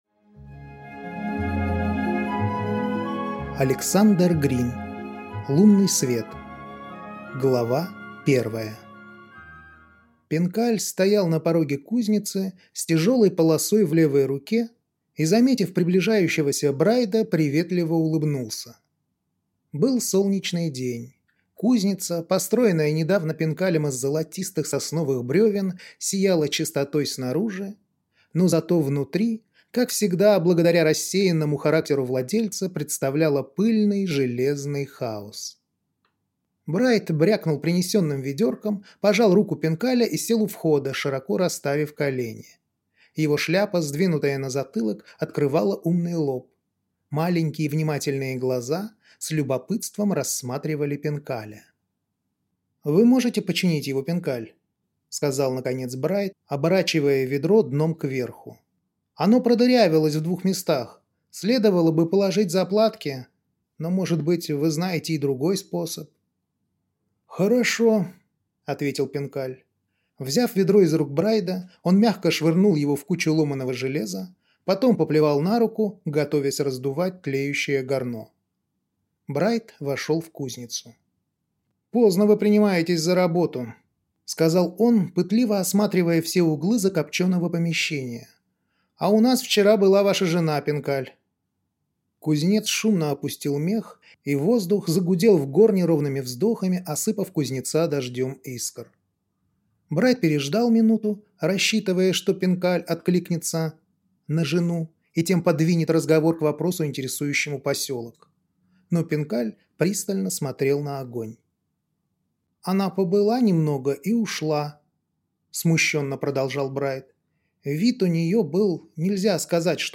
Аудиокнига Лунный свет | Библиотека аудиокниг